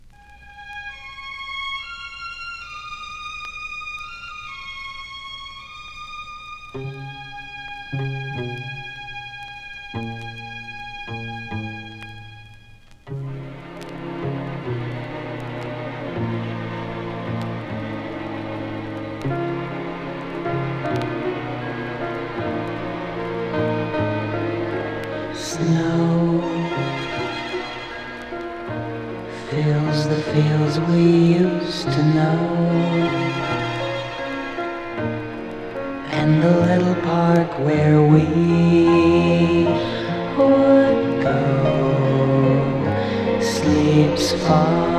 Rock, Pop, Soft Rock　USA　12inchレコード　33rpm　Stereo